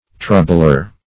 Troubler \Trou"bler\, n.